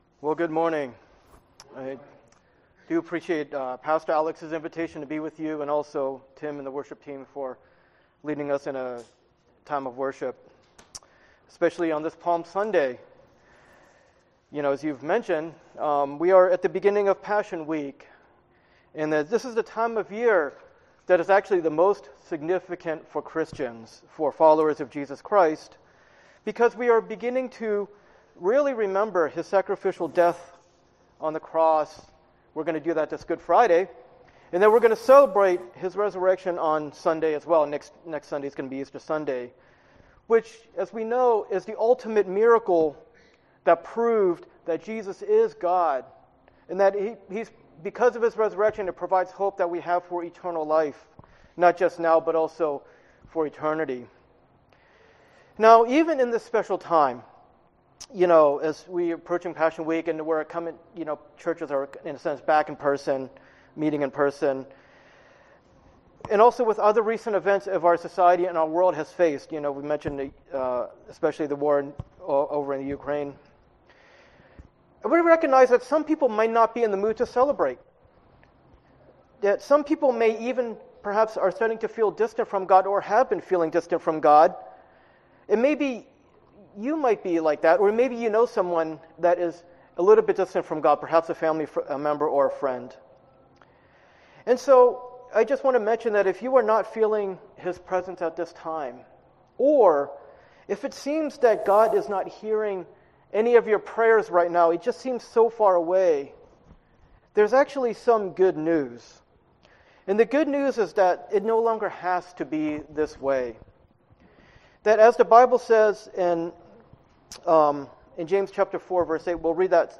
James Passage: James 4:8 Service Type: Sunday Worship 8 Come near to God and he will come near to you.